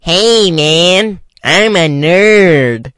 描述：我用书呆子的口吻说嘿，伙计，我是个书呆子。